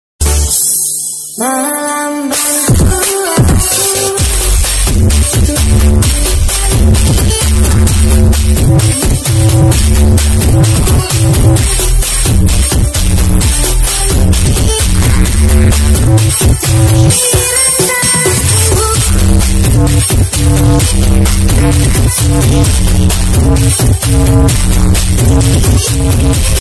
cek sound horeg rumah roboh sound effects free download